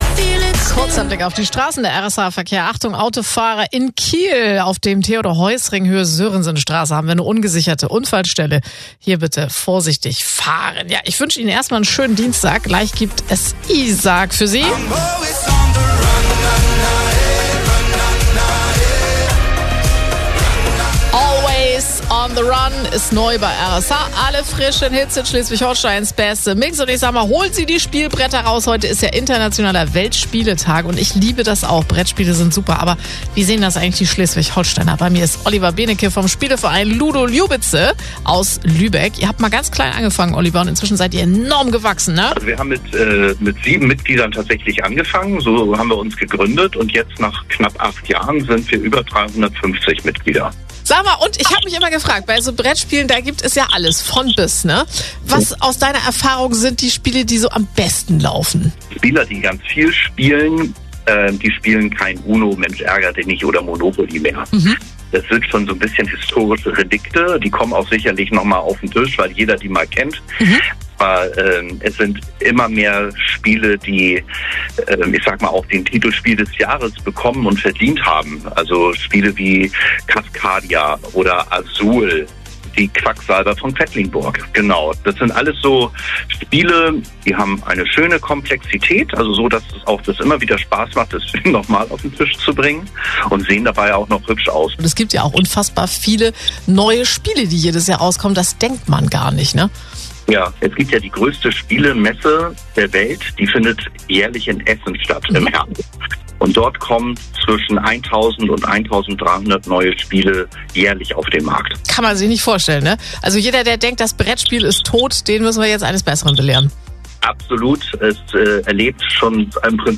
Unser RSH-Interview am Weltspieletag